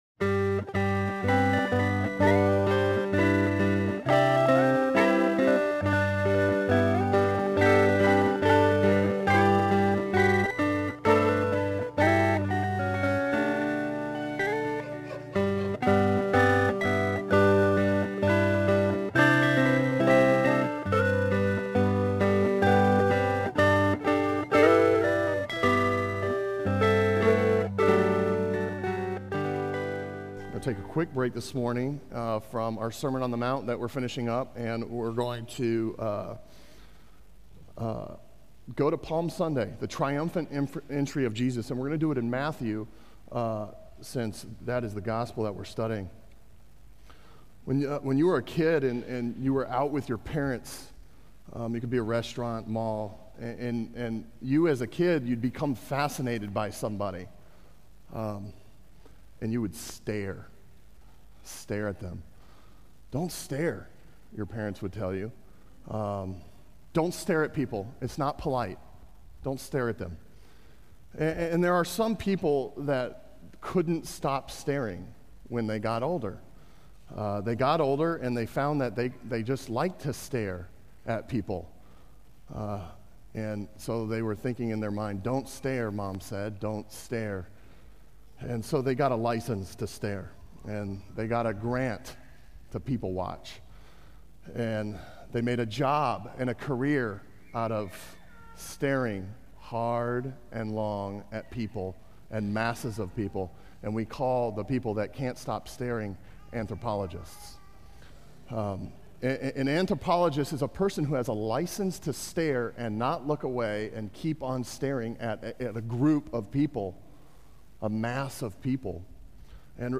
Sermon on Matthew 21:1-11 from April 1